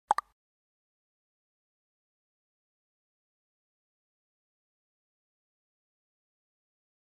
Message sonore - VK